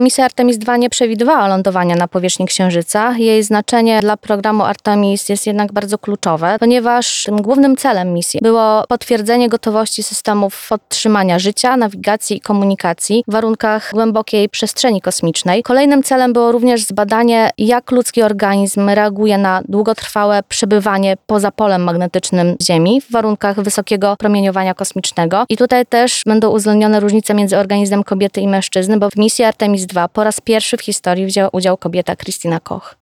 Całość rozmowy dostępna jest na platformach podcastowych: